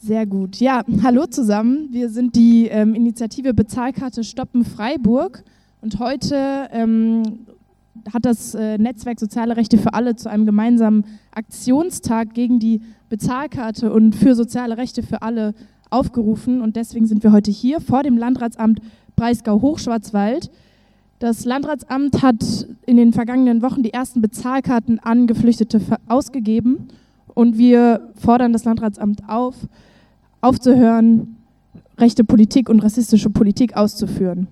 Bald auch Gutschein-Tausch: Kundgebung gegen die Bezahlkarte vor dem Landratsamt in Freiburg